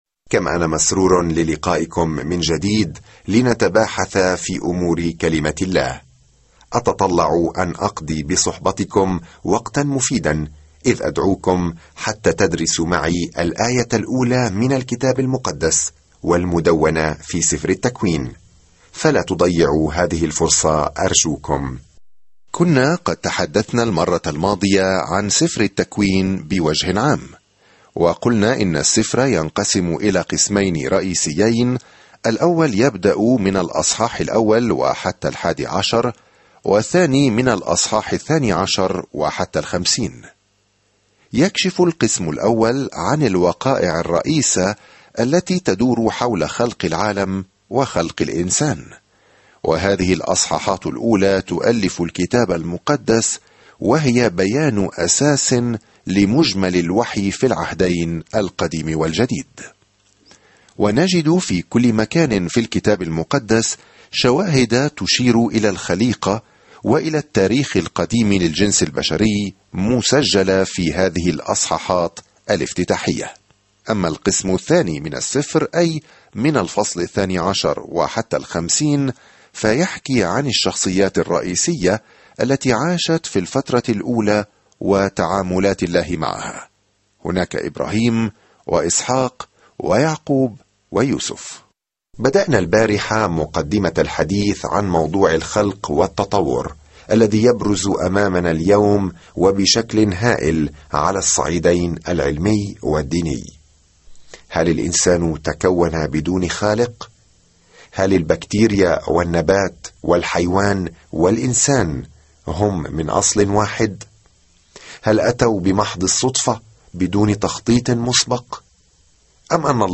سافر يوميًا عبر سفر التكوين وأنت تستمع إلى الدراسة الصوتية وتقرأ آيات مختارة من كلمة الله.